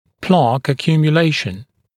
[plɑːk əˌkjuːmjə’leɪʃn][пла:к эˌкйу:мйэ’лэйшн]скопление зубного налета, зубного камня